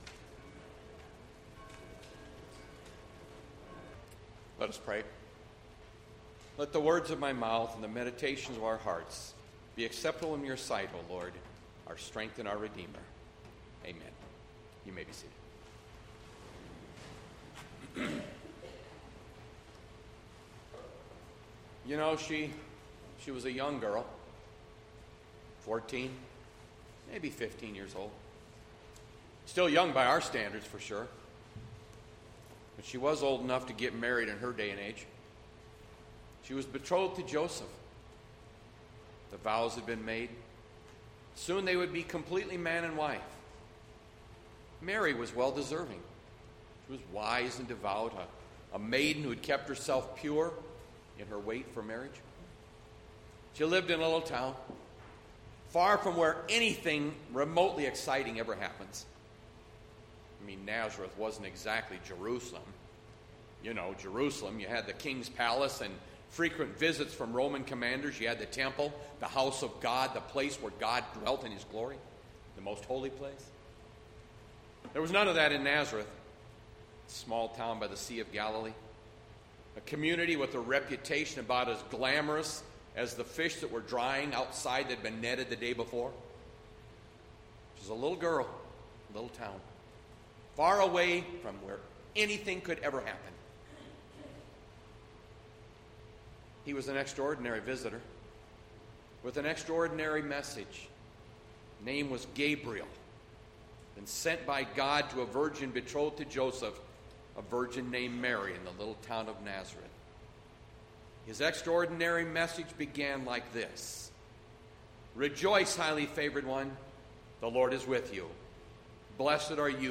Bethlehem Lutheran Church, Mason City, Iowa - Sermon Archive Dec 20, 2020